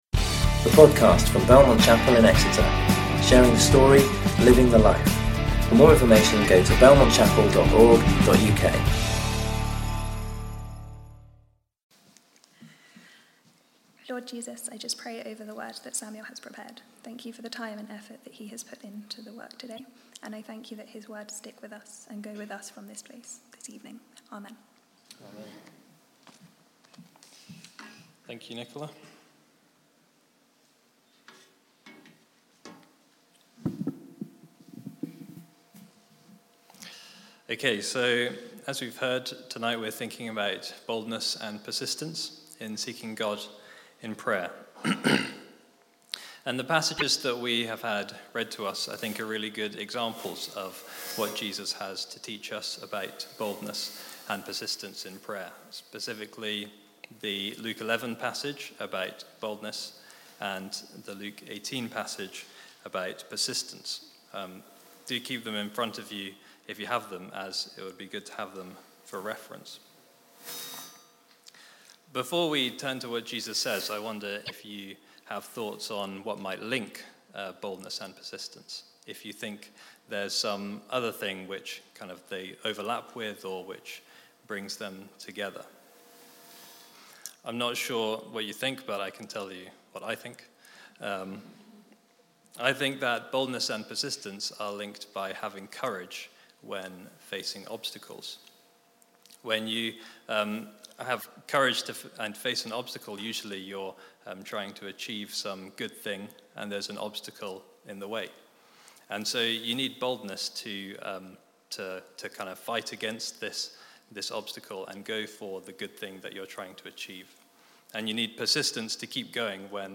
Belmont Exeter's weekly morning and evening service talks.